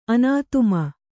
♪ anātuma